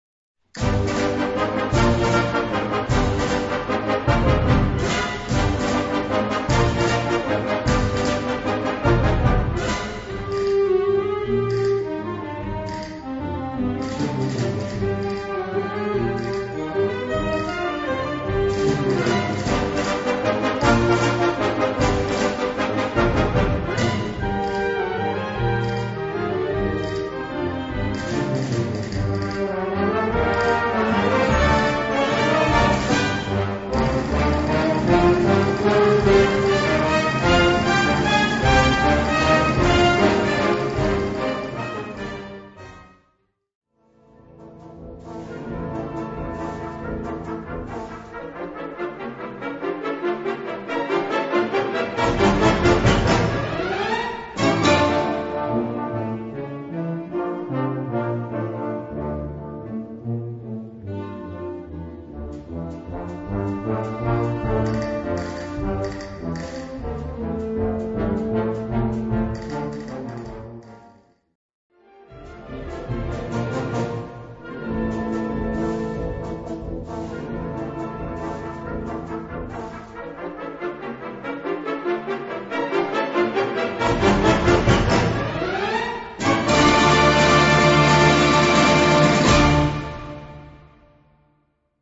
Catégorie Harmonie/Fanfare/Brass-band
Sous-catégorie Musique d'Europe méridionale
Instrumentation Ha (orchestre d'harmonie)